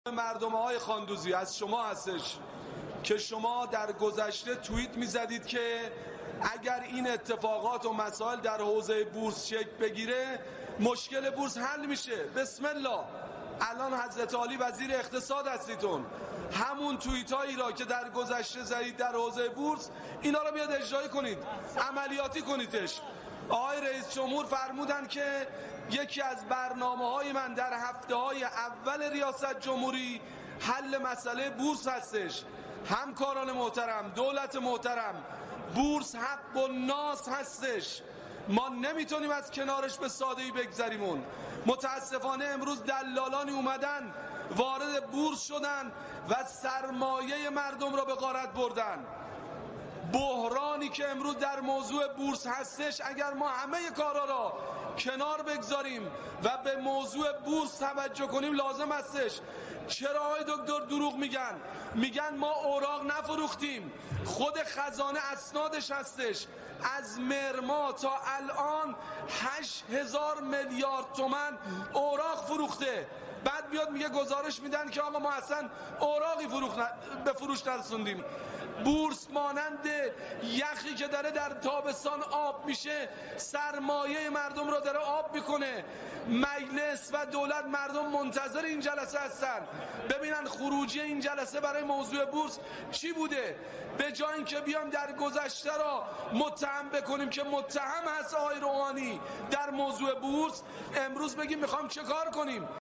نماینده مردم اراک: